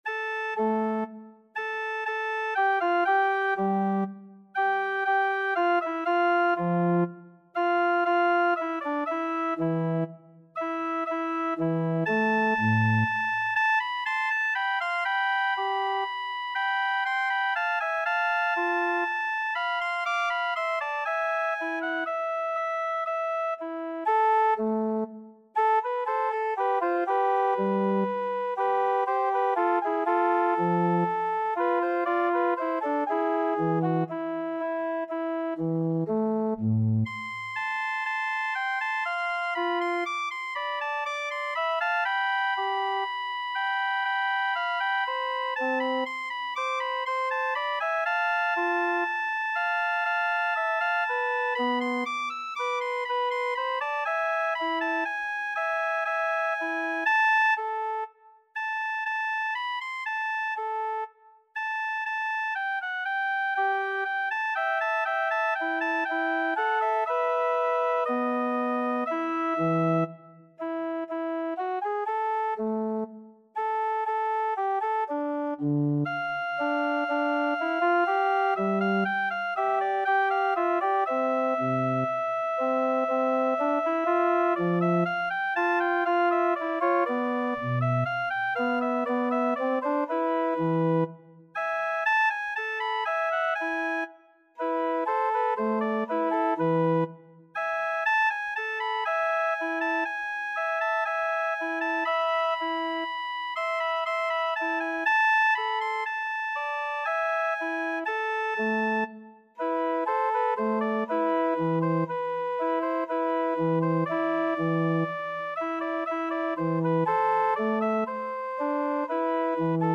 3/4 (View more 3/4 Music)
A minor (Sounding Pitch) (View more A minor Music for Cello )
Adagio
Classical (View more Classical Cello Music)